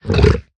minecraft / sounds / mob / hoglin / idle4.ogg